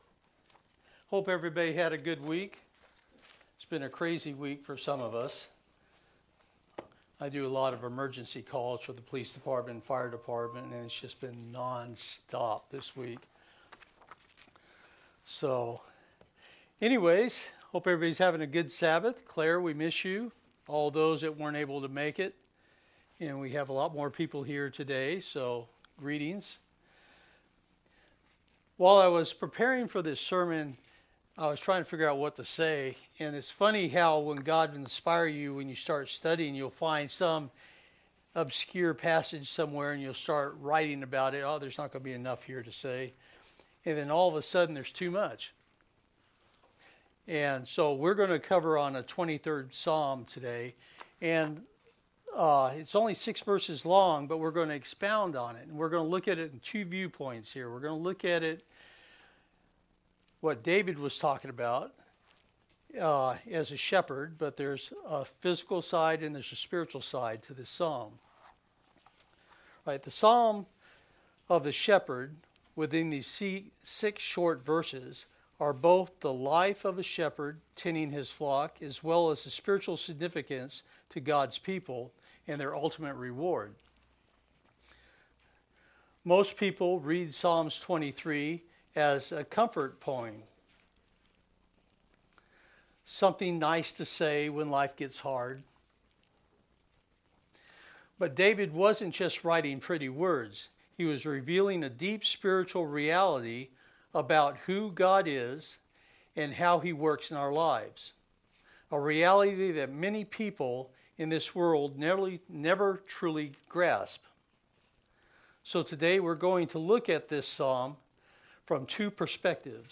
New Sermon | PacificCoG
From Location: "Kennewick, WA"